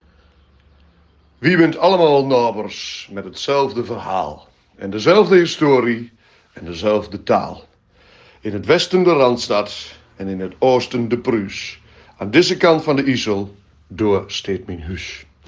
De tekst op de posters is ook ingesproken!